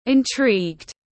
Mưu mô tiếng anh gọi là intrigued, phiên âm tiếng anh đọc là /ɪnˈtriːɡd/
Intrigued /ɪnˈtriːɡd/